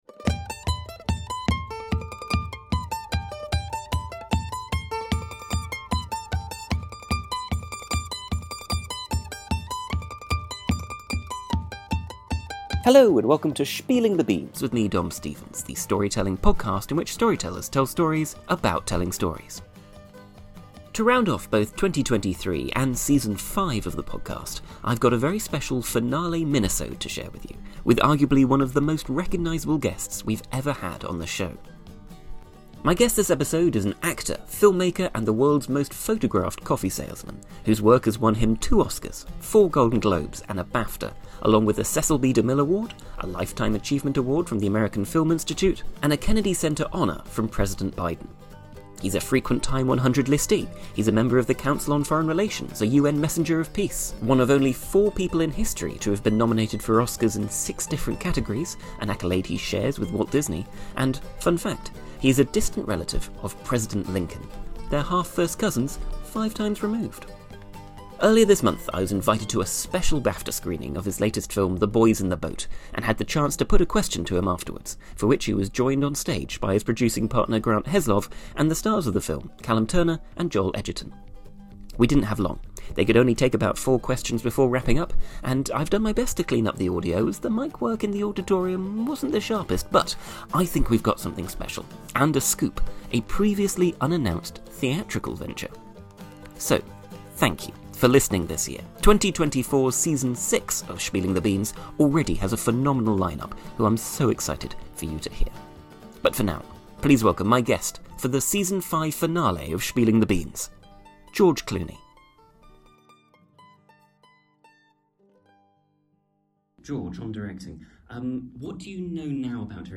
My guest for the Season Five Finale is George Clooney.
Recorded at a special BAFTA screening of his latest film 'The Boys In The Boat', in December 2023.